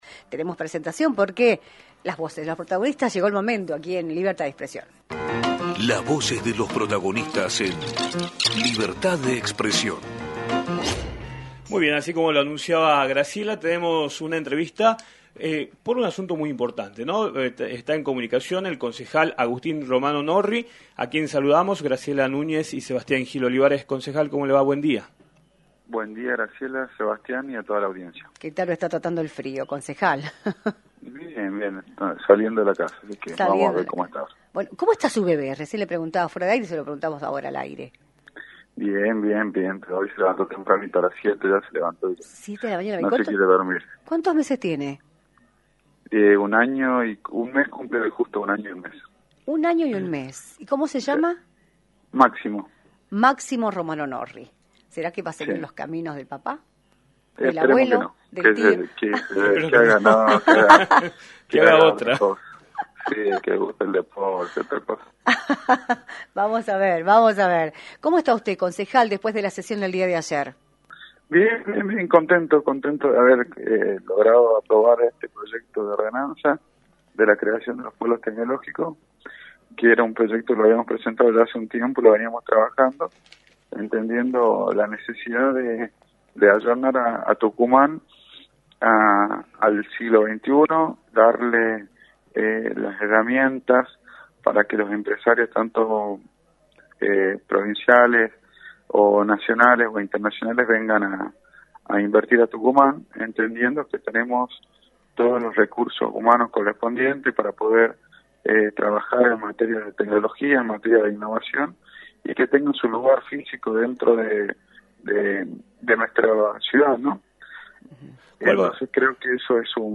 Agustín Romano Norri, Concejal de San Miguel de Tucumán y Legislador electo, informó en “Libertad de Expresión”, por la 106.9, los alcances del proyecto de ordenanza que implica la creación de polos tecnológicos en la ciudad, el cual fue aprobado el jueves en el Concejo Deliberante, además de analizar la política nacional y provincial.